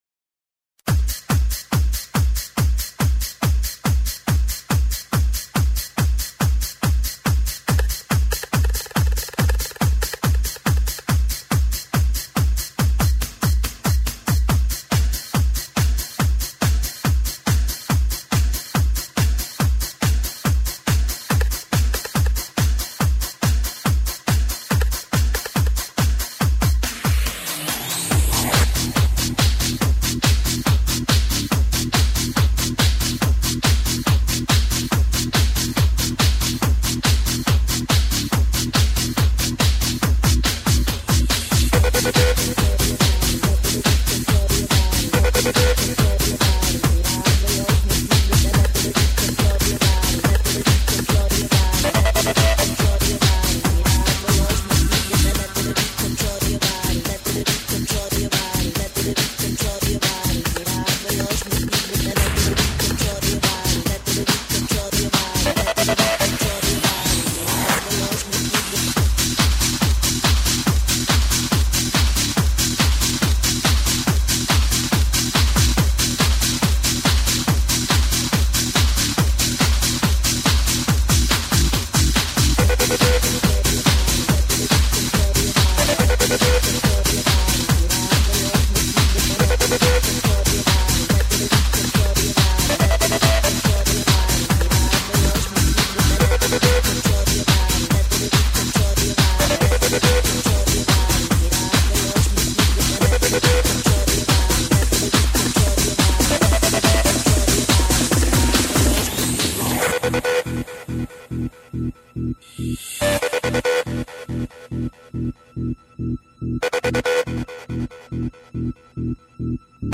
Žánr: Pop
Cover remixes